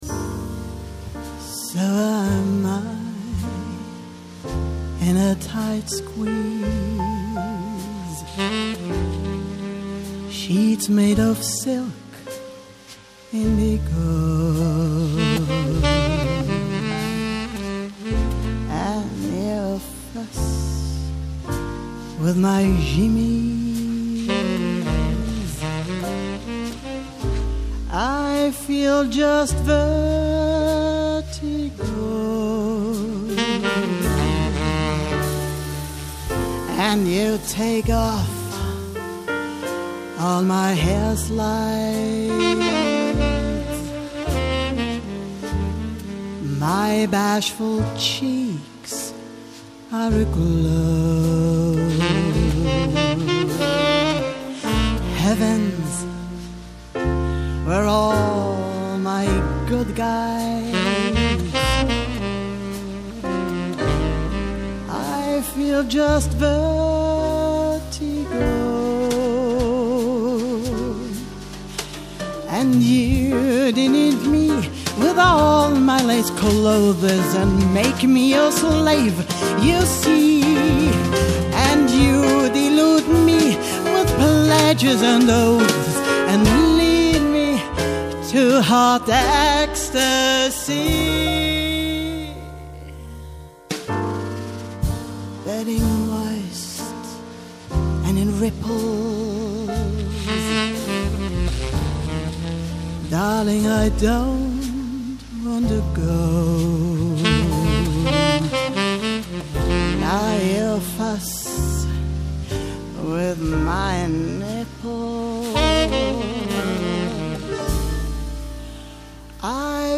Jazz – vocal